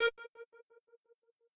synth1_18.ogg